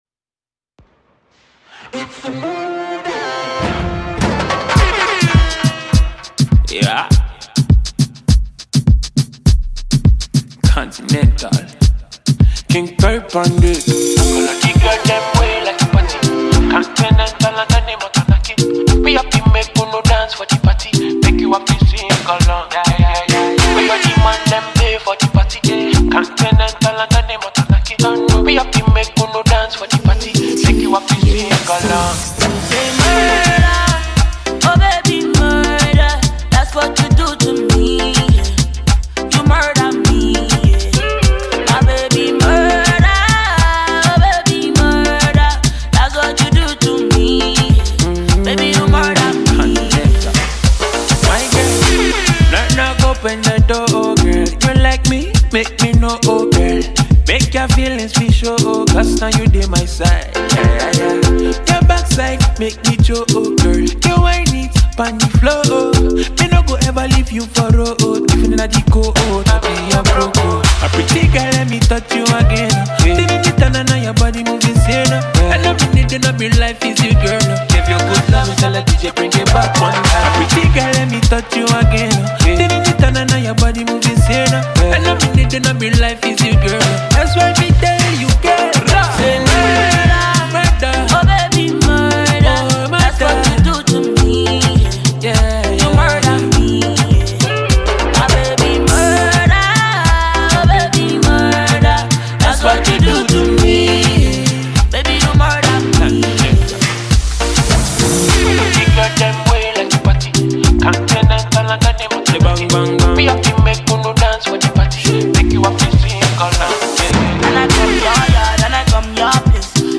it’s a classic for lovers.